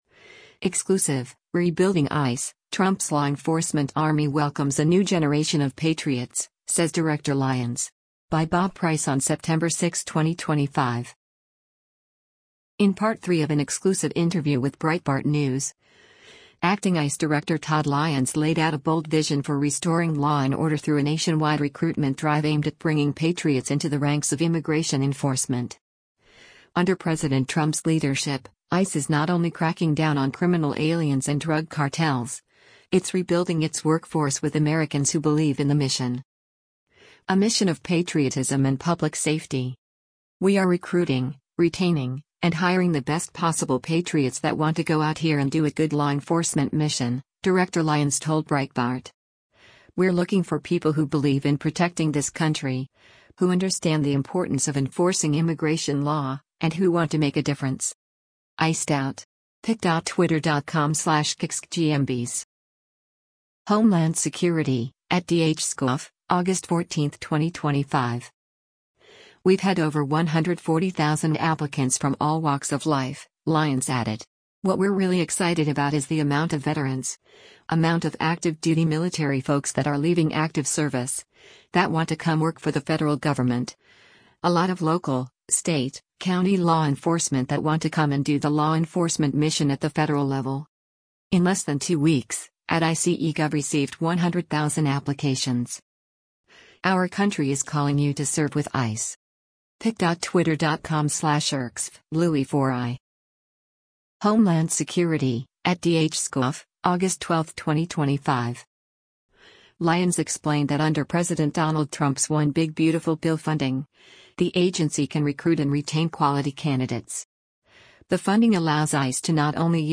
Editor’s Note: This is Part Three of a three-part exclusive interview with Acting ICE Director Todd Lyons.